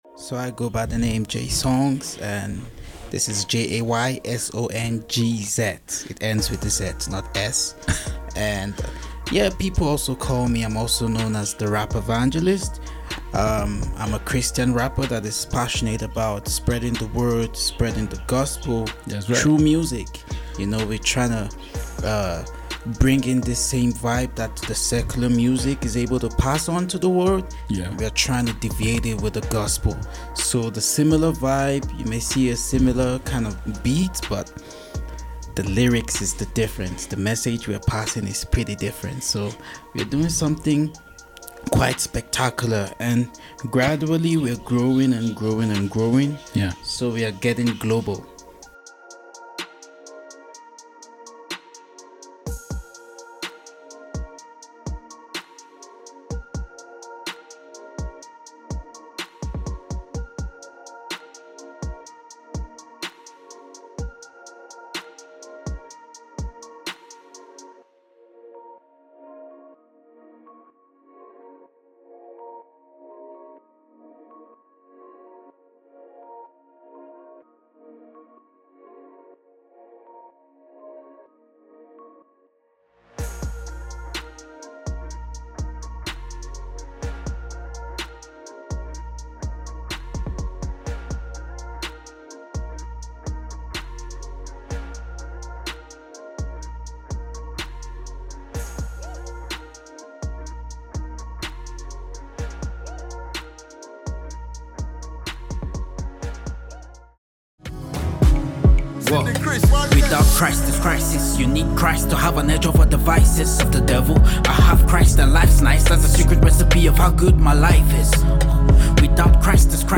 Play Rate Listened List Bookmark Get this podcast via API From The Podcast All posted contents were live broadcast and have been made available for your listening pleasure.